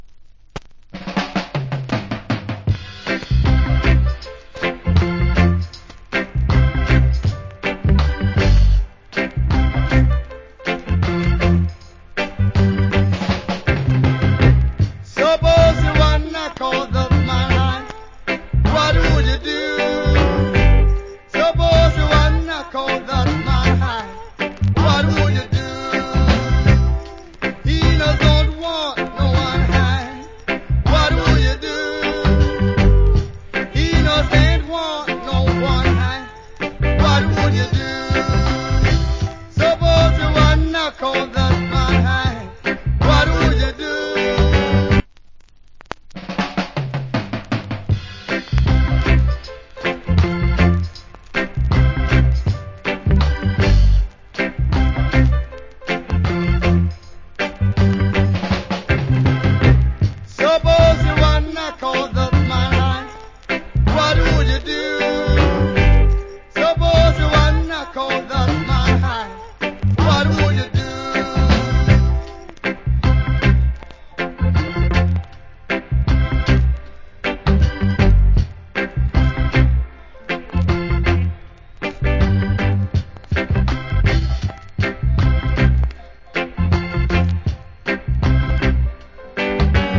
コメント Good Reggae Vocal. / Version.